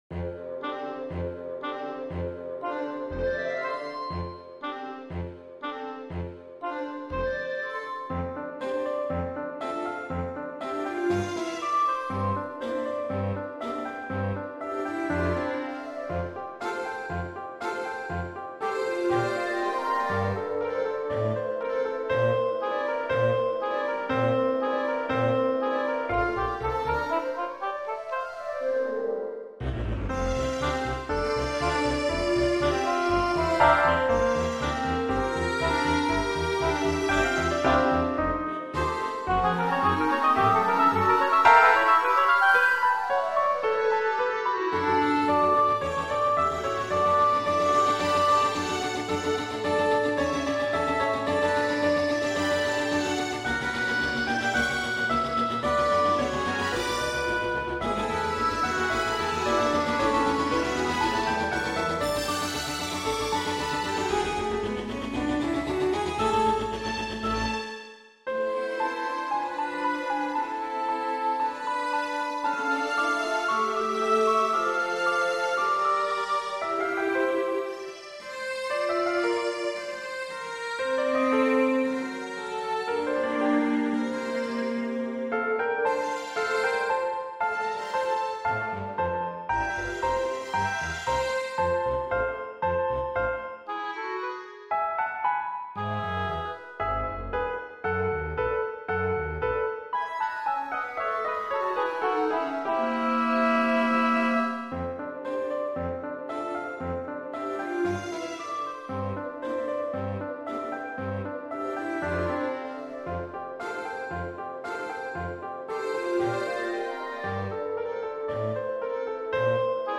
(Dur)